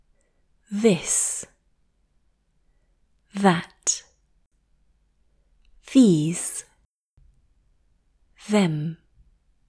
2. Struggling with the English “th” sounds